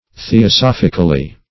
theosophically.mp3